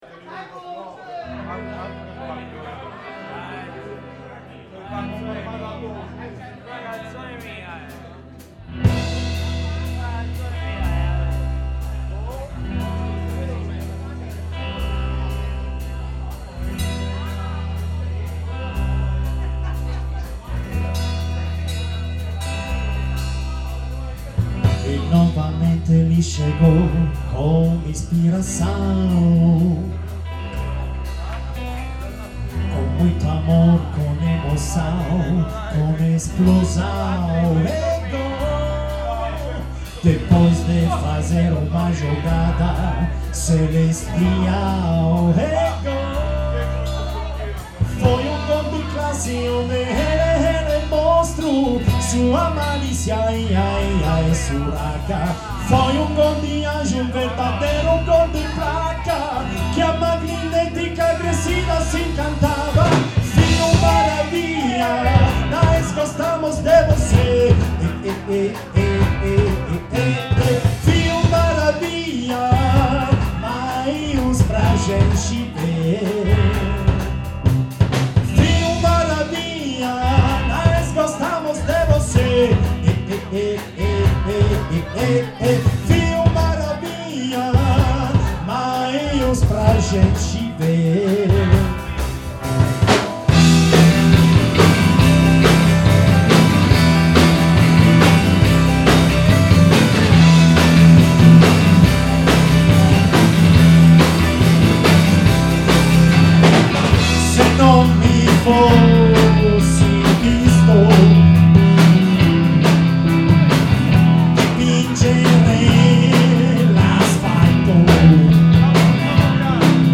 with ORTF stereo configuration,